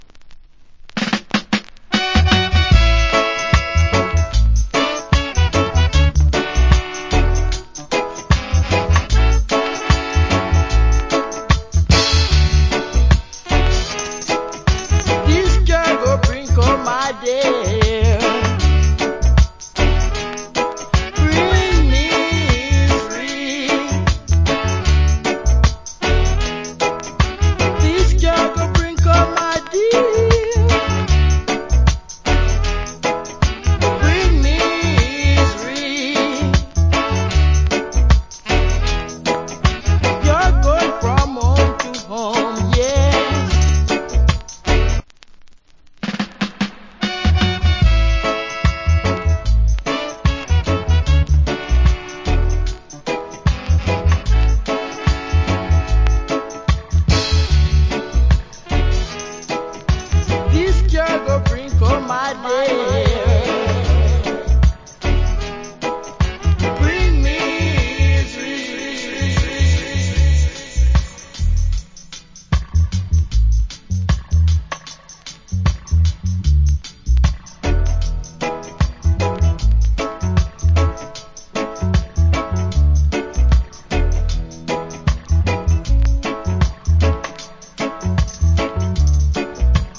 Good Roots Vocal.